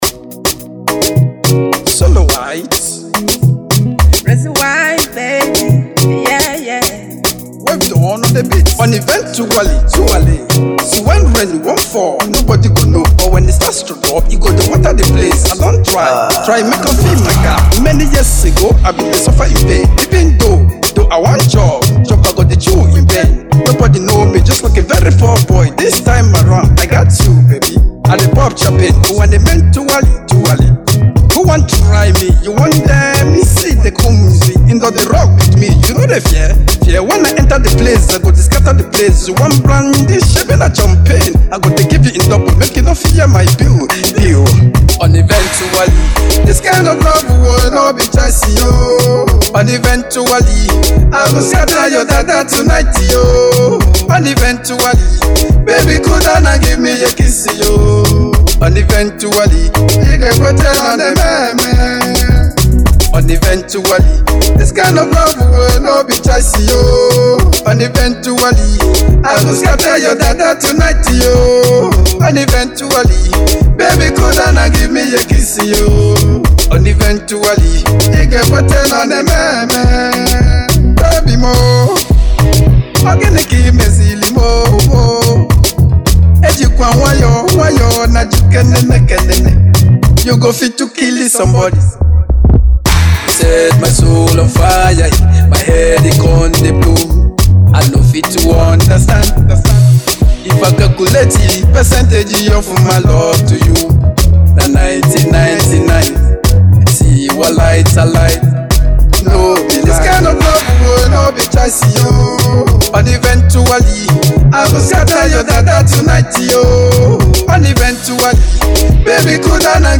Category : Hip Hop Subategory : Conscious Hip Hop Free (0) 0